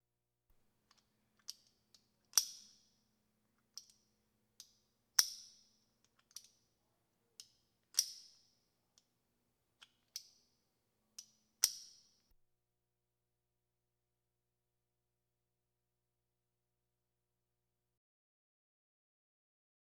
transport
Car Seat Belt Safety Harness Clip And Unclip